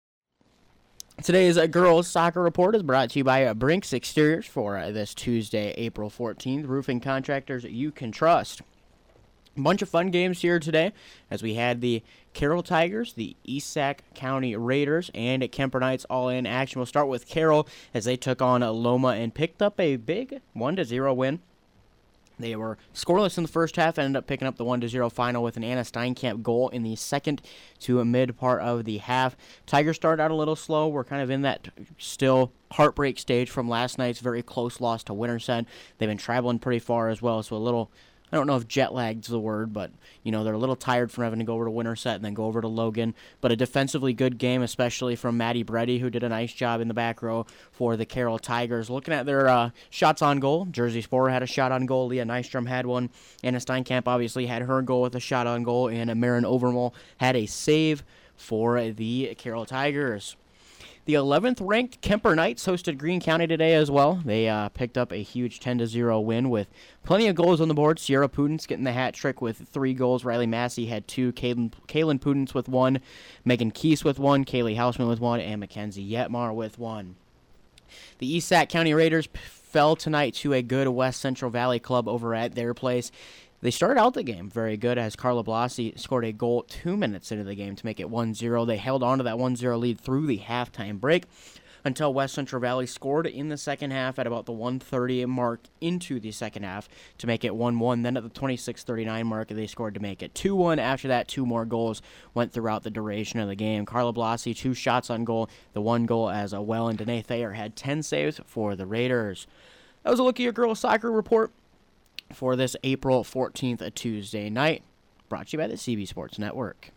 Below is an Audio Recap of Girls’ Soccer Results from Tuesday, April 14th